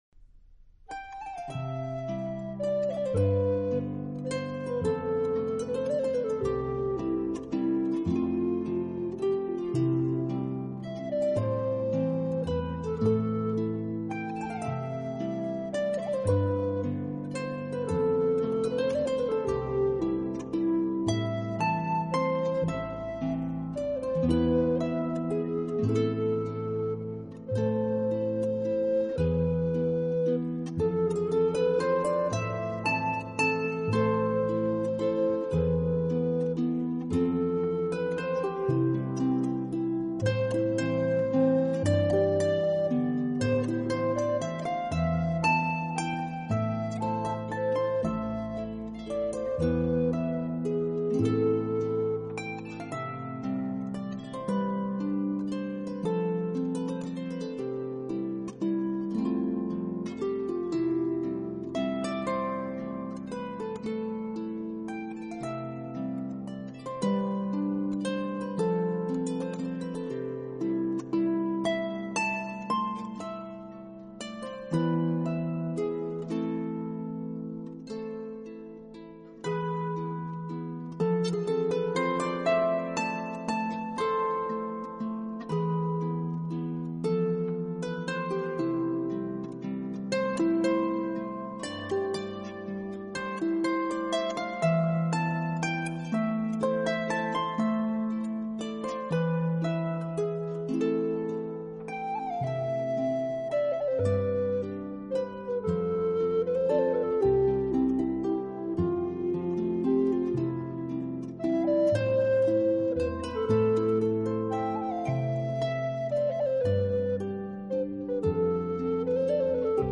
版本：Celtic